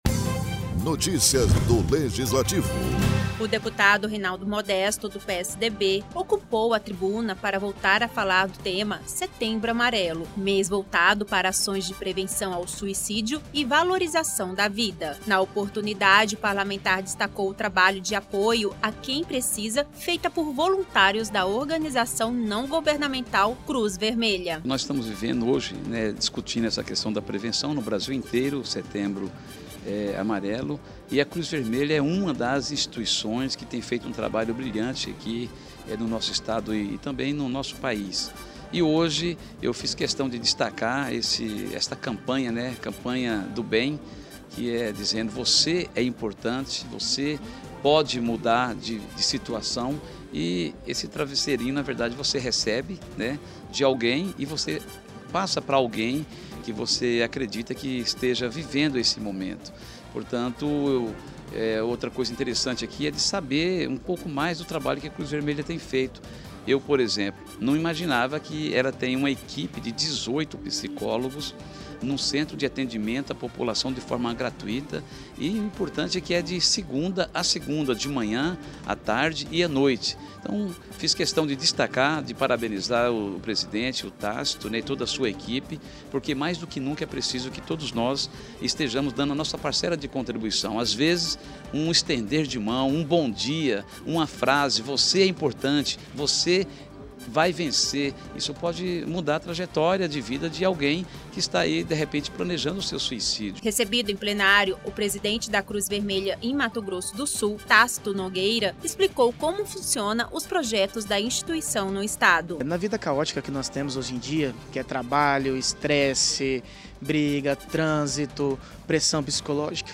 O deputado Rinaldo Modesto (PSDB) subiu à tribuna nesta terça-feira (17) para falar do tema: setembro amarelo, mês voltado para ações de prevenção ao suicídio e valorização da vida.